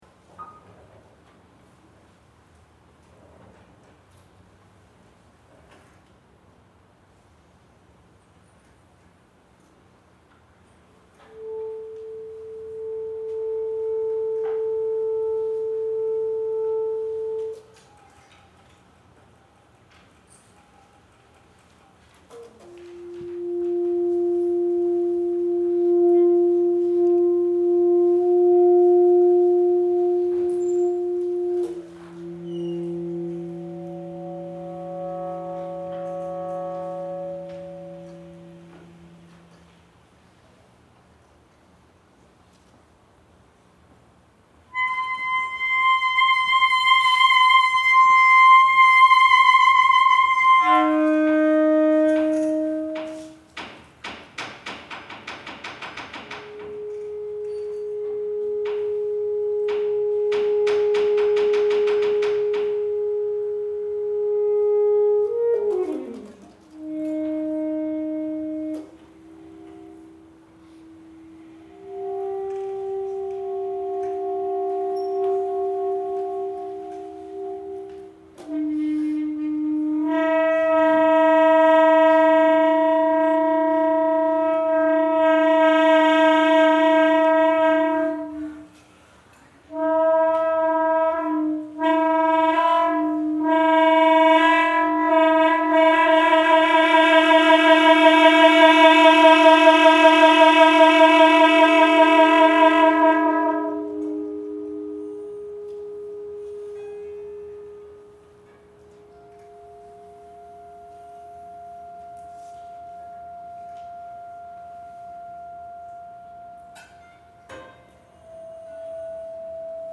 performed on a concert of new works at Southeastern Louisiana University
piano
alto saxophone.
The only hitch was of course due to technology (thankfully my piece was completely acoustic this time).
Here they are getting ready to perform: I was able to get a decent recording this time.
Here’s the recording from the concert I recorded on my Sony PCM-D50.
Acoustic Music , Saxophone